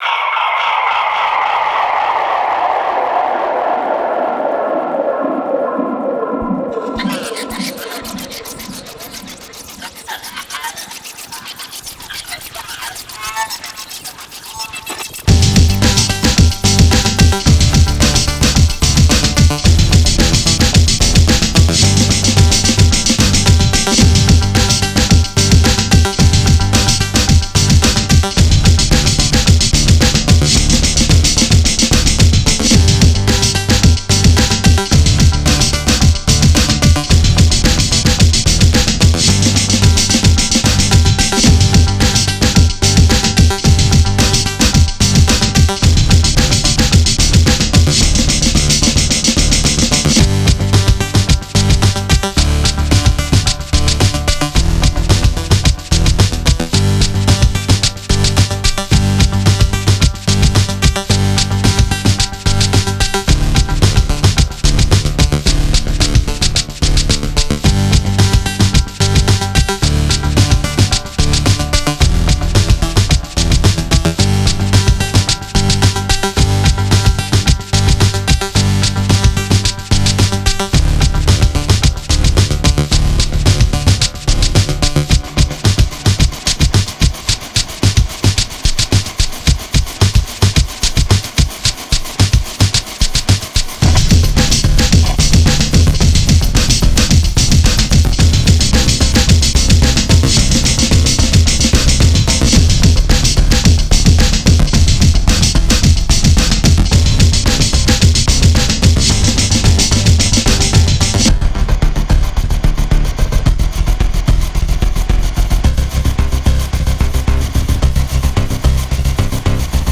breakcore, noise,